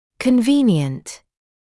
[kən’viːnɪənt][кэн’виːниэнт]удобный, подходящий